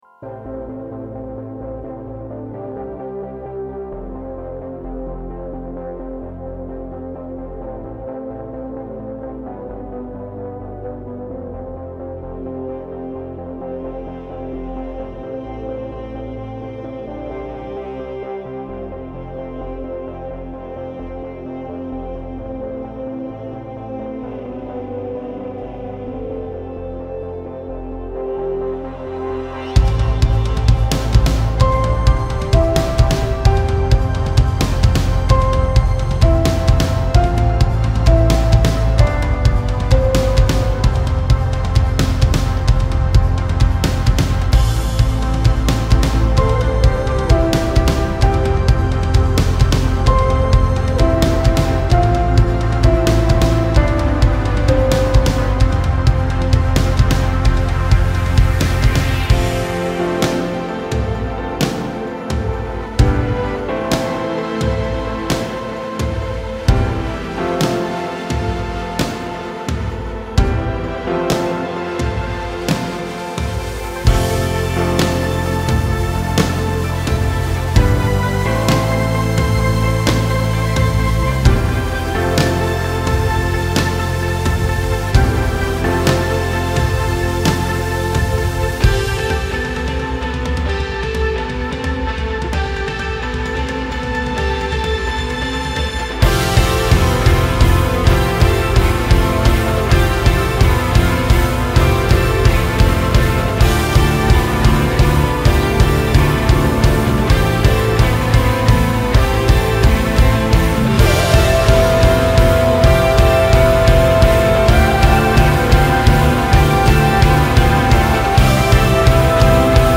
Instrumental: